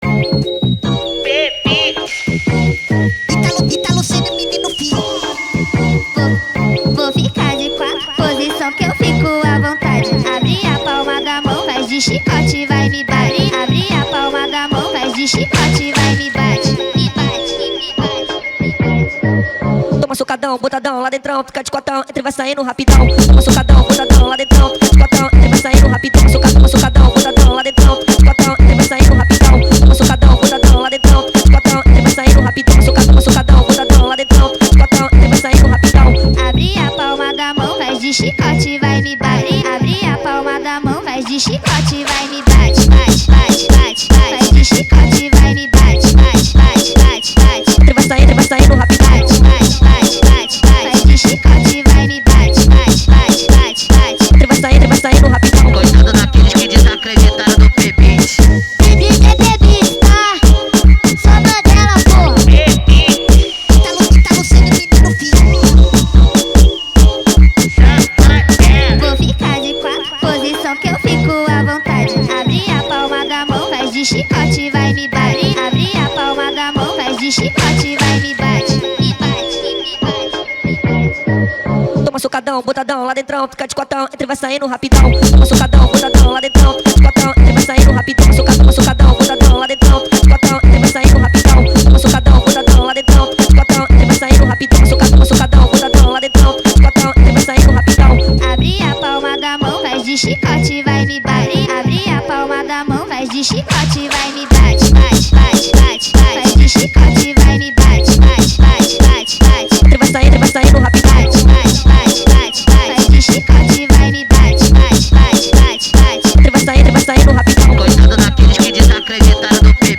TikTok Remix Speed Version